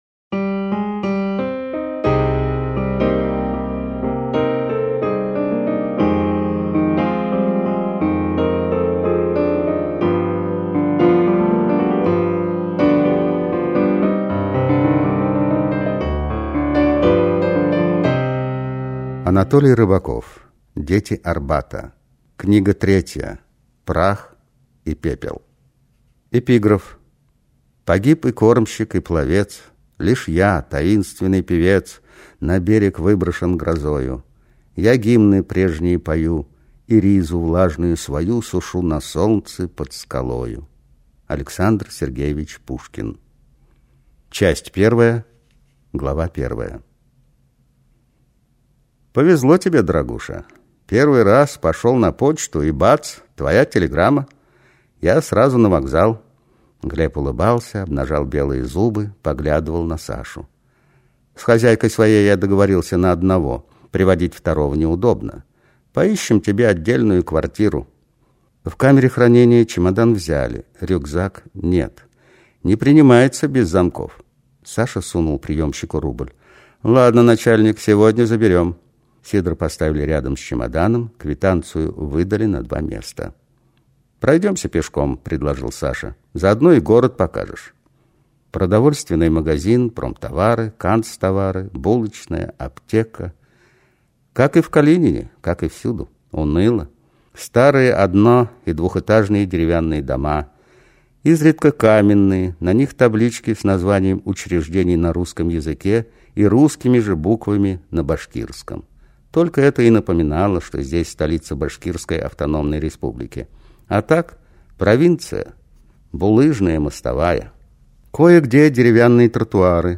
Аудиокнига Дети Арбата 3 Прах и пепел | Библиотека аудиокниг